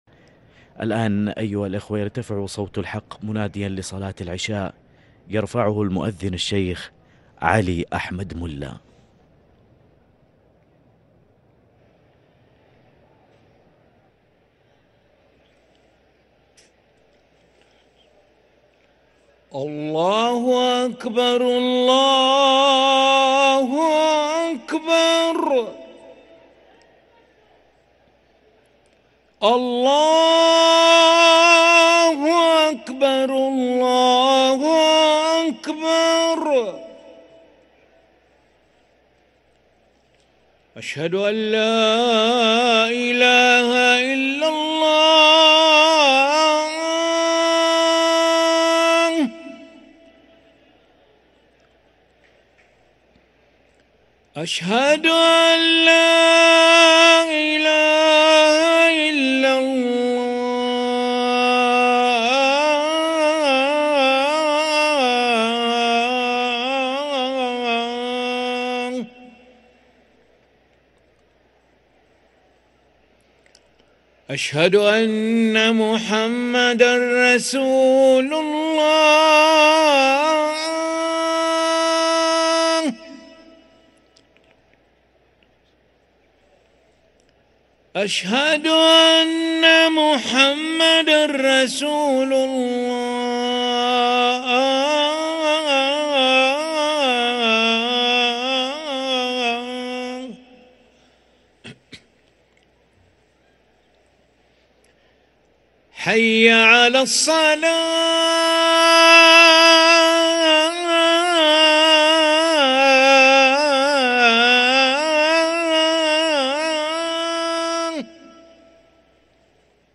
أذان العشاء للمؤذن علي ملا الأحد 25 رمضان 1444هـ > ١٤٤٤ 🕋 > ركن الأذان 🕋 > المزيد - تلاوات الحرمين